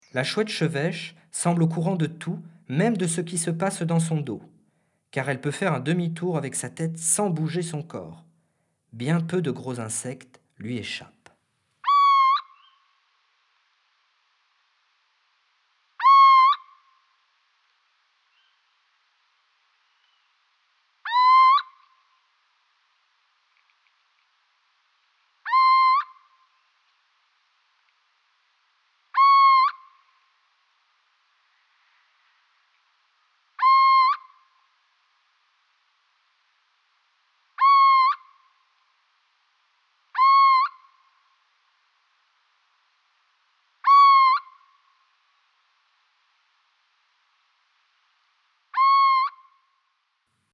Cri perçant et chant rappelant un miaulement.